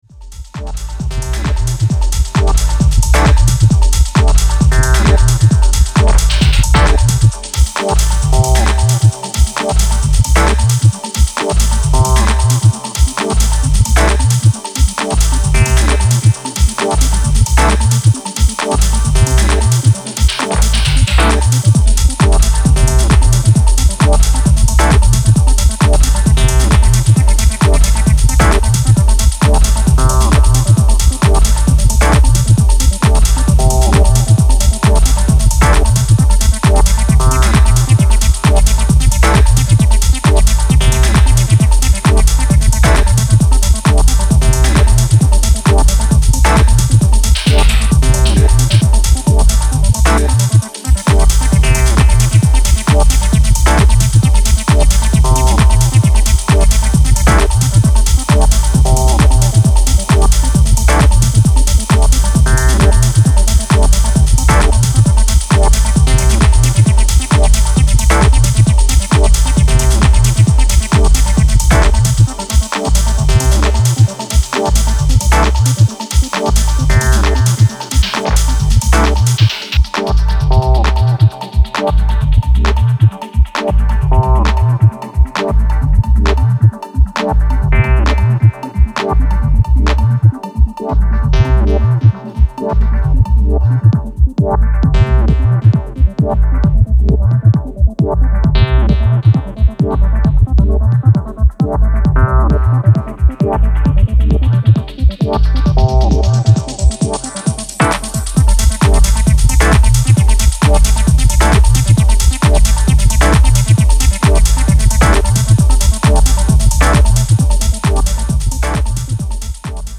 Electro/ Techno grooves!